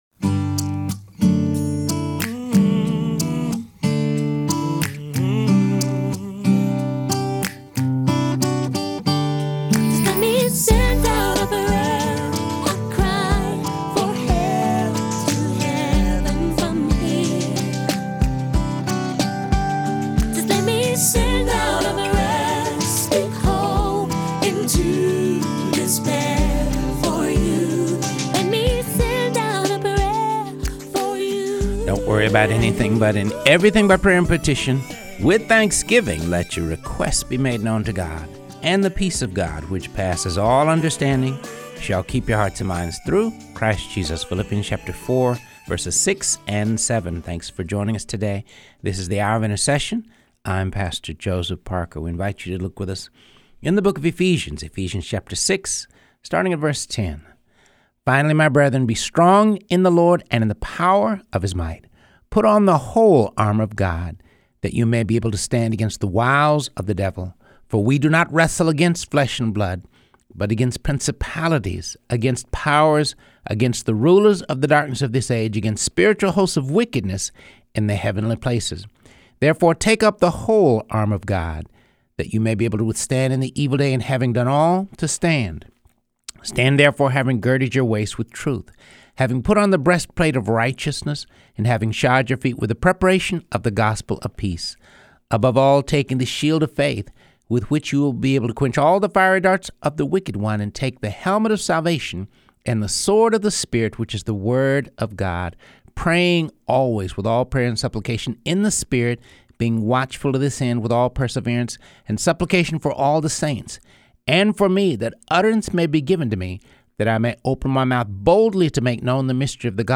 reads through the Bible.